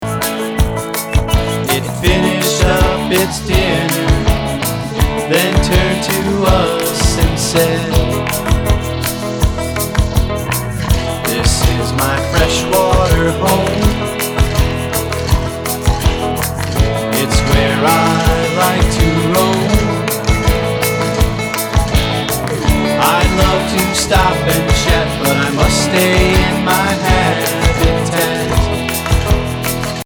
Tuneful and bubbly